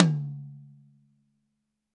Index of /90_sSampleCDs/ILIO - Double Platinum Drums 1/CD4/Partition E/GRETSCH TOMD